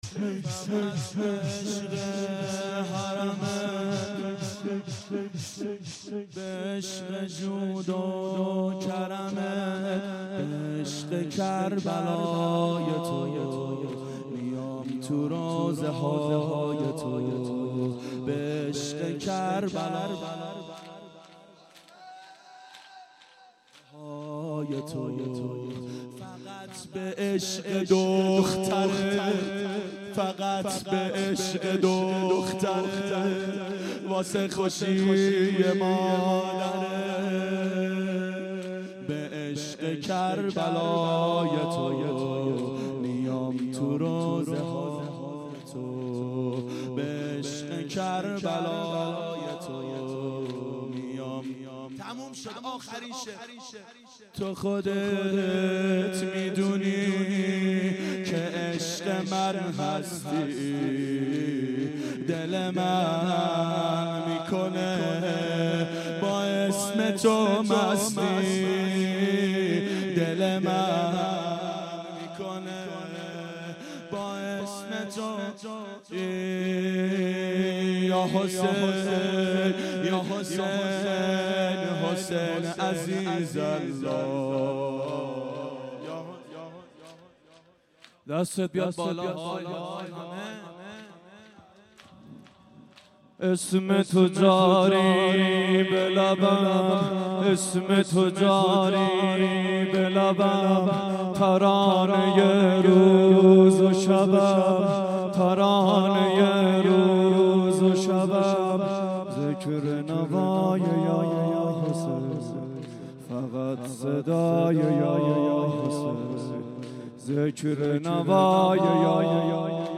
• دهه اول صفر سال 1392 هیئت شیفتگان حضرت رقیه سلام الله علیها